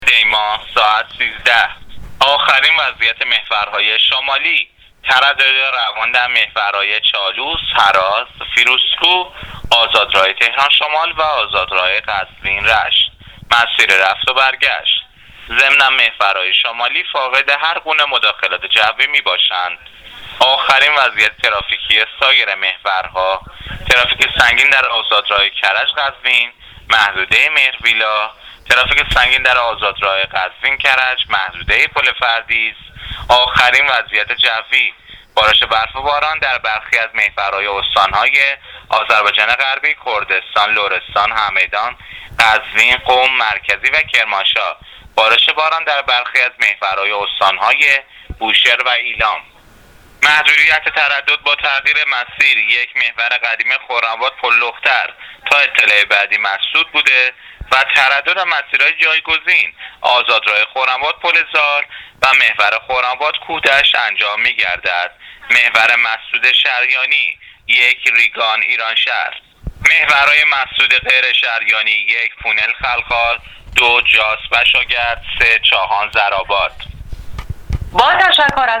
گزارش رادیو اینترنتی از آخرین وضعیت ترافیکی جاده‌ها تا ساعت ۱۳بیست و چهارم دی؛